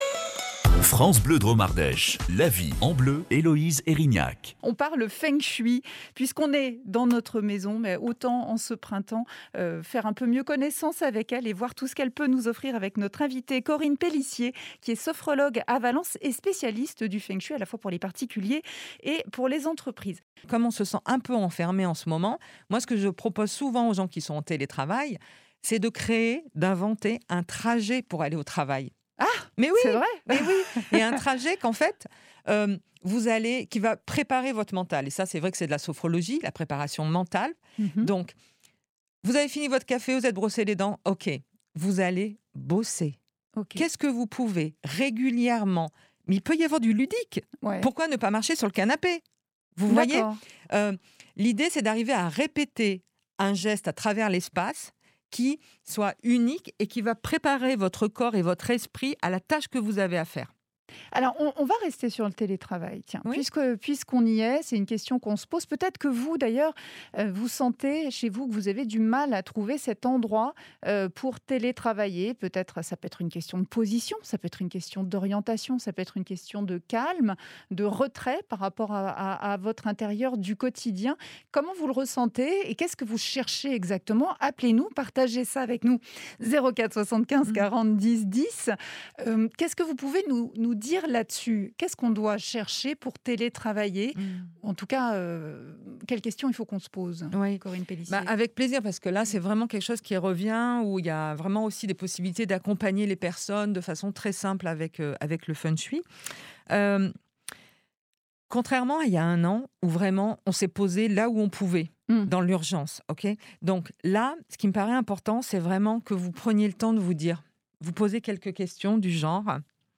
EMISSION-FRANCE-BLEU-AVRIL-2021-TELETRAVAIL-DES-CONSEILS-POUR-VOTRE-QUOTIDIEN.mp3